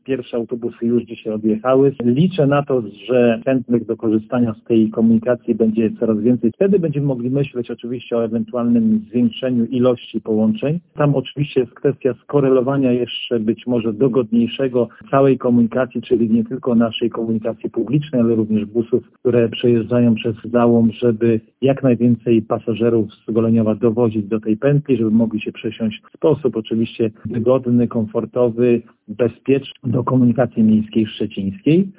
– Mówi Tomasz Banach, zastępca burmistrza gminy Goleniów.